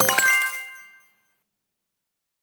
Special & Powerup (21).wav